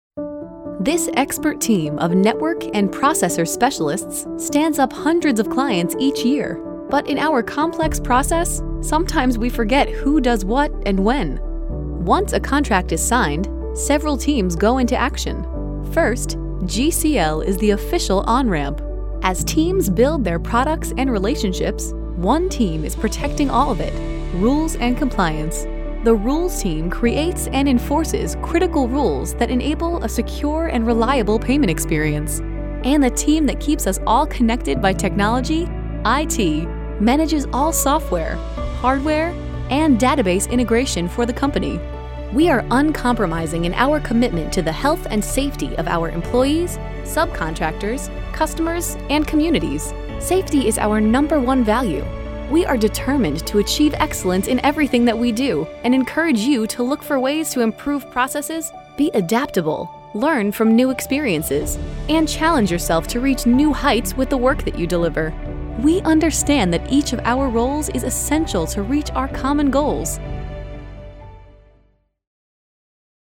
Female Voice Over, Dan Wachs Talent Agency.
Bright, Friendly, Warm, Credible.
eLearning